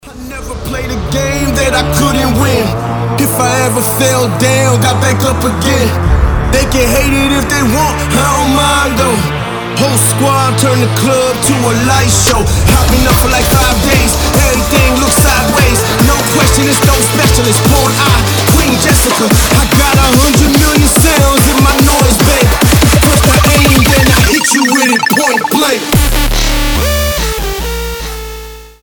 • Качество: 320, Stereo
жесткие
мощные
басы
быстрые
Trapstep
Качёвый дабстеп